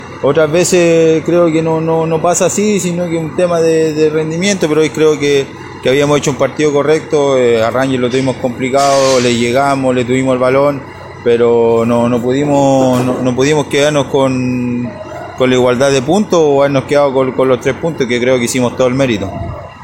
Así lo indicó en diálogo con Pasión Unionista.